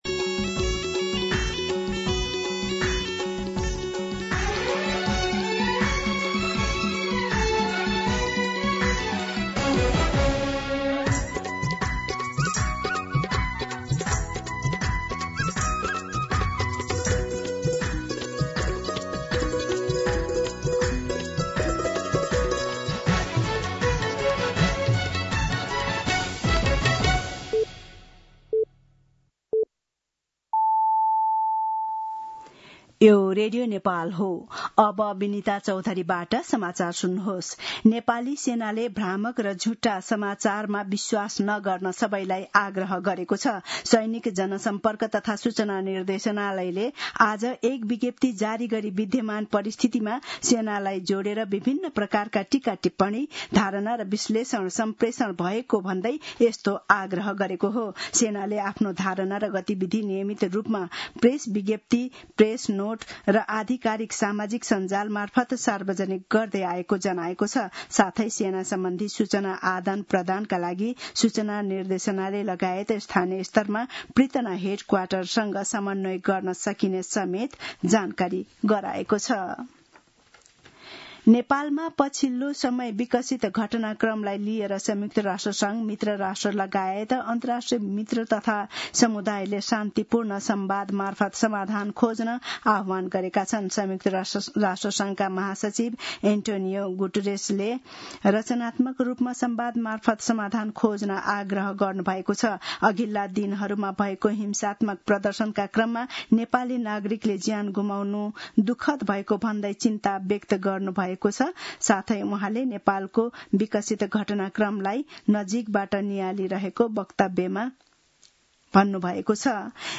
दिउँसो १ बजेको नेपाली समाचार : २७ भदौ , २०८२